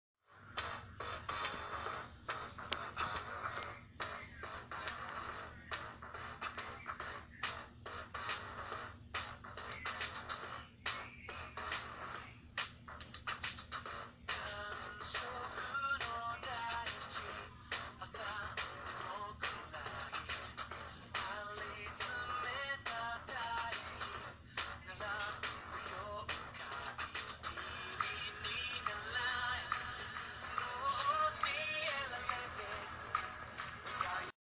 Download Blizzard sound effect for free.
Blizzard